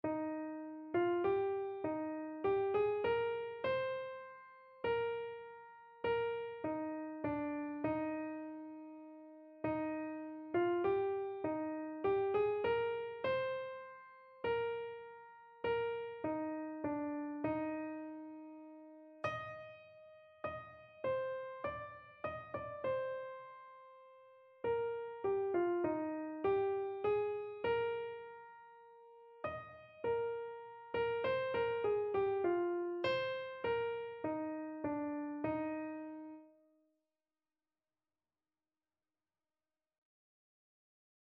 Christian Christian Keyboard Sheet Music He Who Would Valiant Be (Monks Gate)
Free Sheet music for Keyboard (Melody and Chords)
Eb major (Sounding Pitch) (View more Eb major Music for Keyboard )
4/4 (View more 4/4 Music)
Keyboard  (View more Intermediate Keyboard Music)
Classical (View more Classical Keyboard Music)